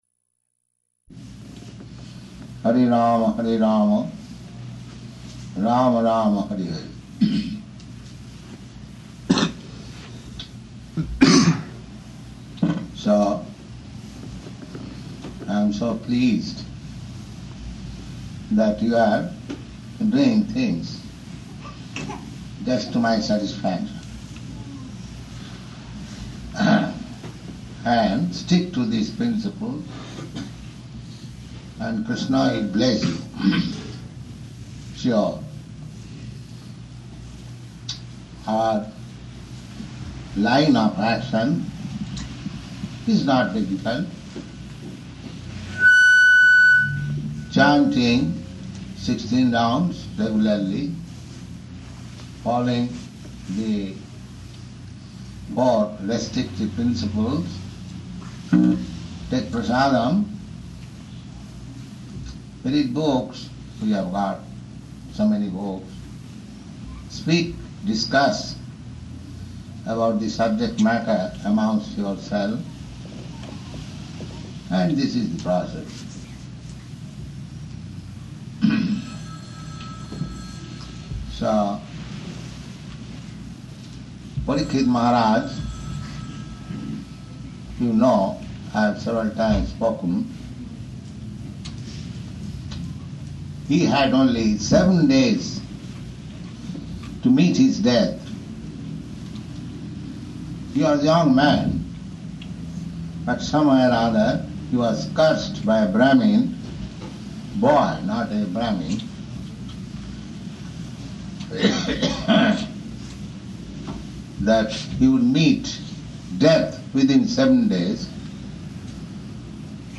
Śrīmad-Bhāgavatam 2.1.1–5 [First Return from Europe] --:-- --:-- Type: Srimad-Bhagavatam Dated: December 22nd 1969 Location: Boston Audio file: 691222SB-BOSTON.mp3 Prabhupāda: ...Hare Rāma, Hare Rāma, Rāma Rāma, Hare Hare.